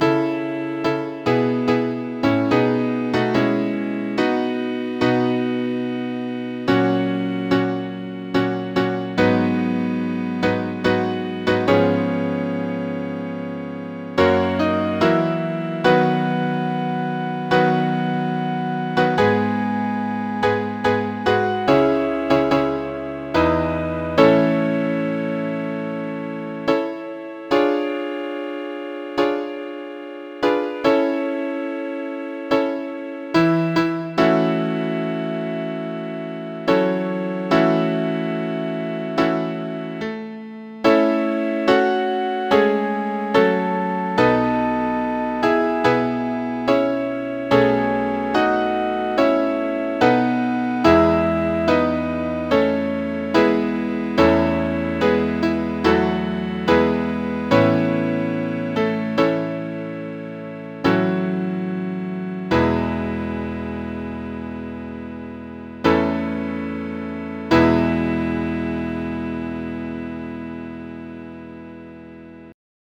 Audition Cut Audio Files
Mid-State Women (Gretchaninoff)